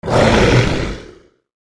gnoll_commander_attack.wav